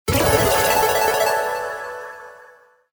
wheel_win_01.mp3